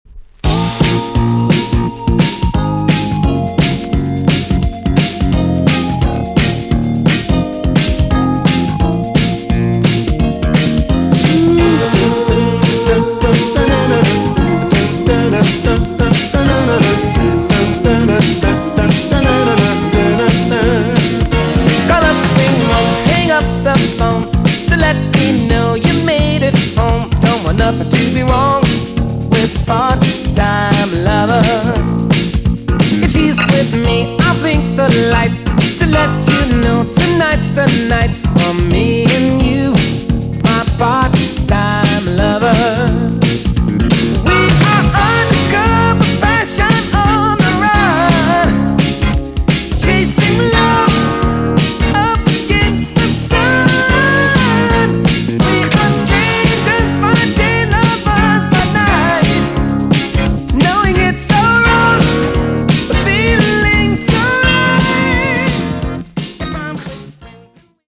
EX 音はキレイです。
WICKED DANCE CLASSIC TUNE!!